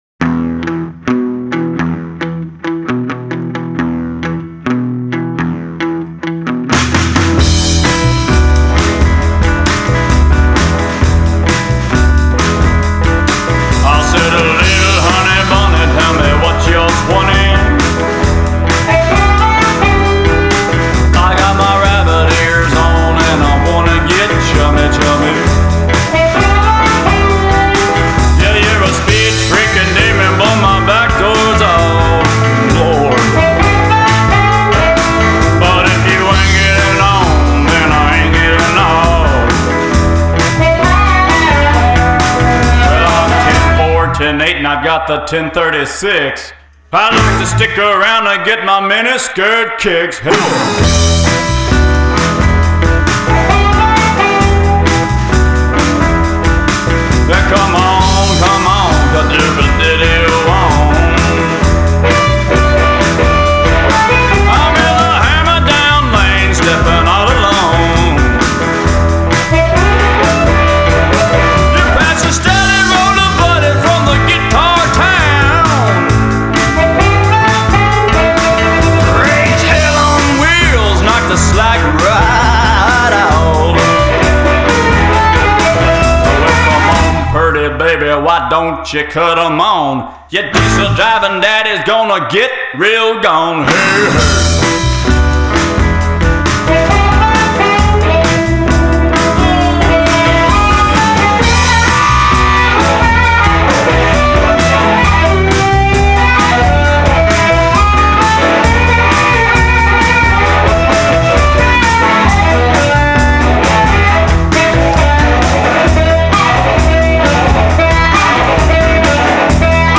create a fertile and jumpy hybrid.